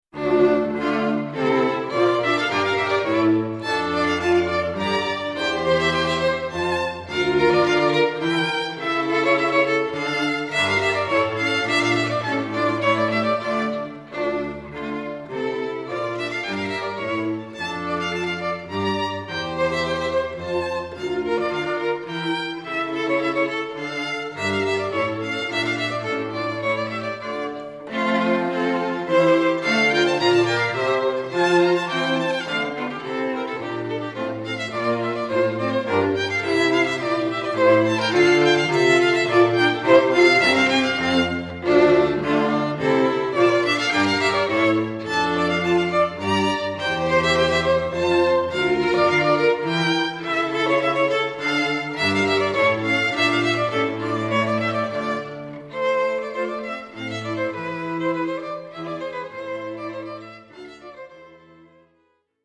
Click the blue titles below to hear Cotswold Ensemble string quartet players performing.